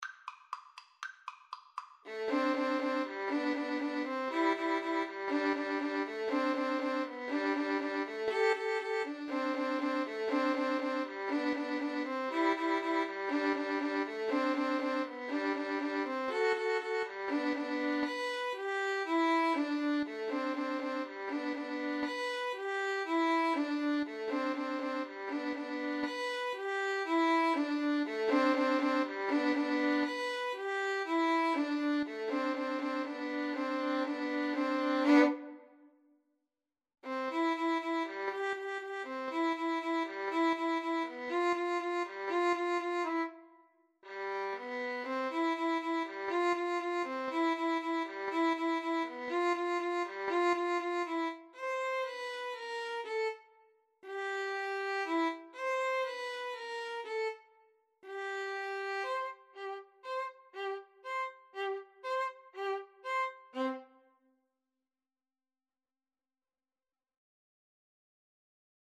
Allegro = 120 (View more music marked Allegro)
Violin Duet  (View more Easy Violin Duet Music)
Classical (View more Classical Violin Duet Music)